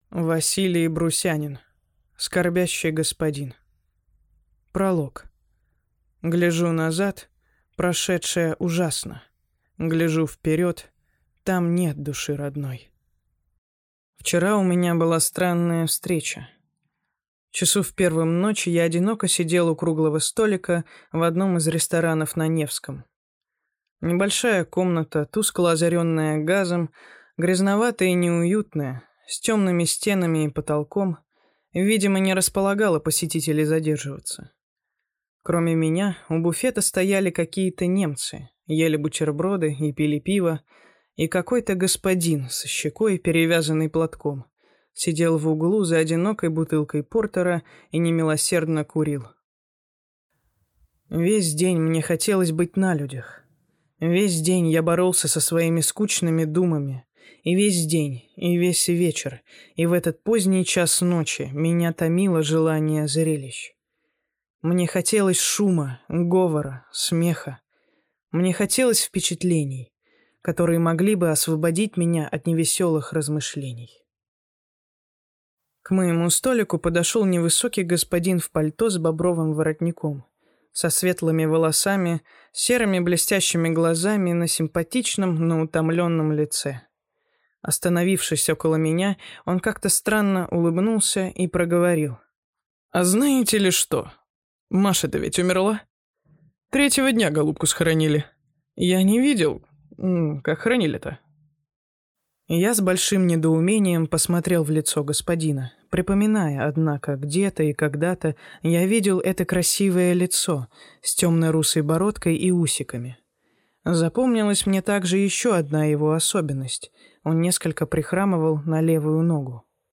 Аудиокнига Скорбящий господин | Библиотека аудиокниг